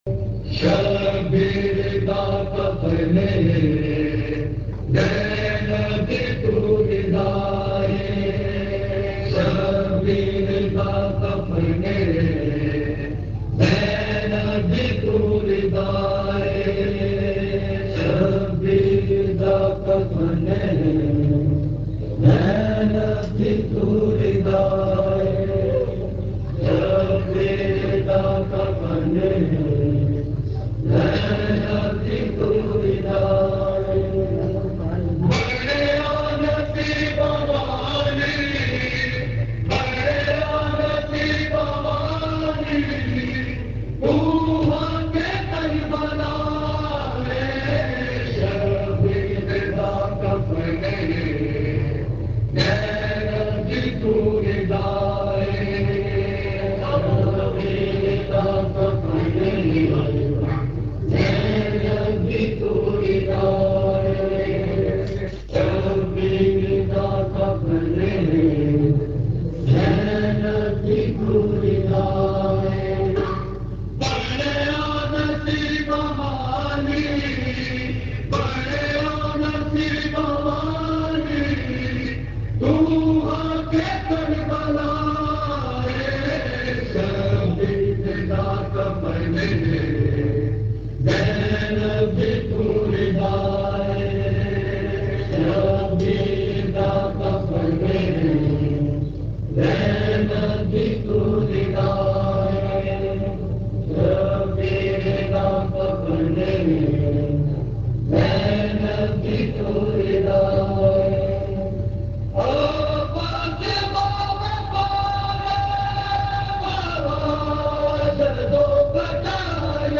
Katree Bawa, Chuna Mandee, Lahore
Recording Type: Live